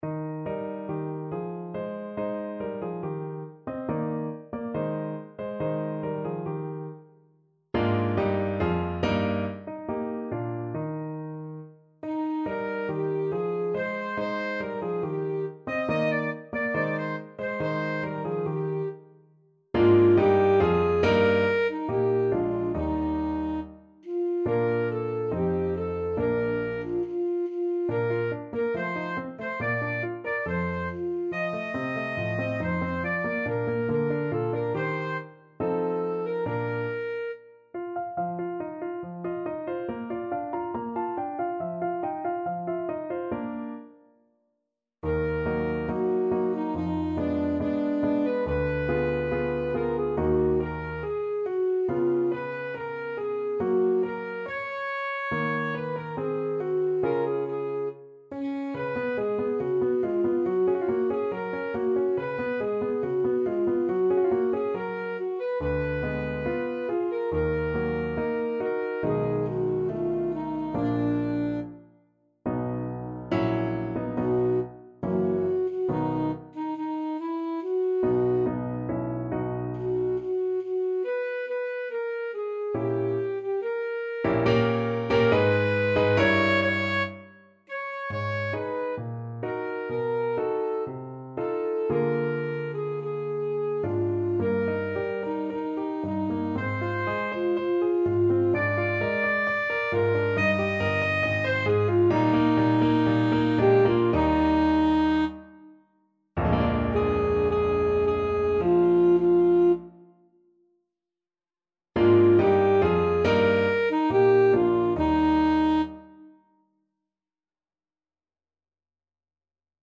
Classical Mozart, Wolfgang Amadeus Das Veilchen, K.476 Alto Saxophone version
Alto Saxophone
Eb major (Sounding Pitch) C major (Alto Saxophone in Eb) (View more Eb major Music for Saxophone )
2/4 (View more 2/4 Music)
= 70 Allegretto
Classical (View more Classical Saxophone Music)